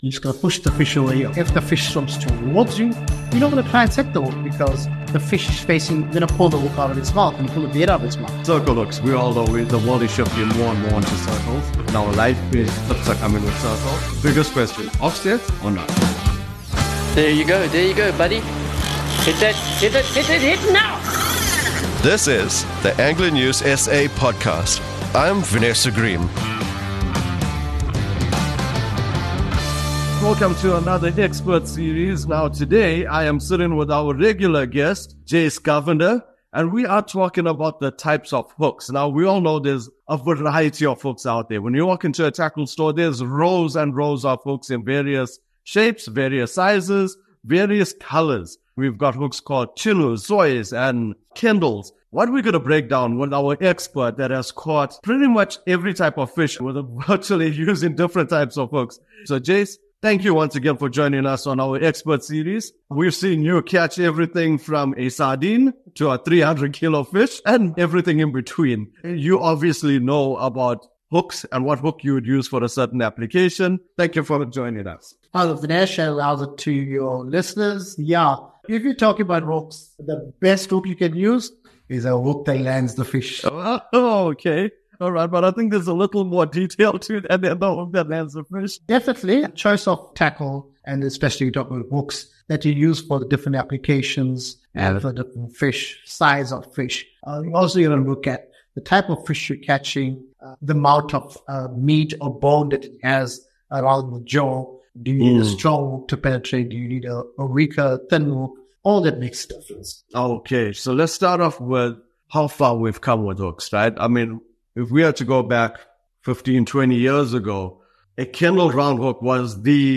interviews fishing specialist